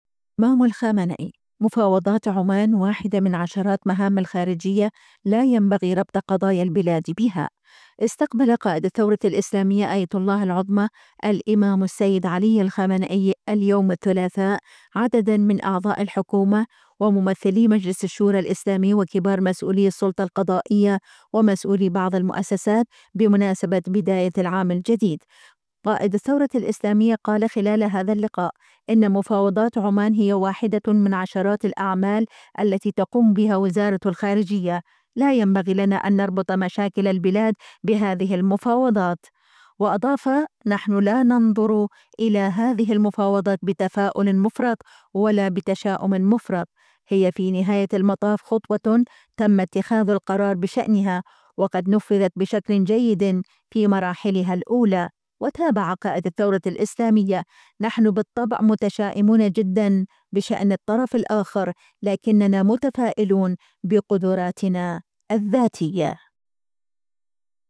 استقبل قائد الثورة الإسلامية آية الله العظمى الإمام السيد علي الخامنئي، اليوم الثلاثاء، عددا من أعضاء الحكومة وممثلي مجلس الشورى الإسلامي وكبار مسؤولي السلطة القضائية ومسؤولي بعض المؤسسات، بمناسبة بداية العام الجديد.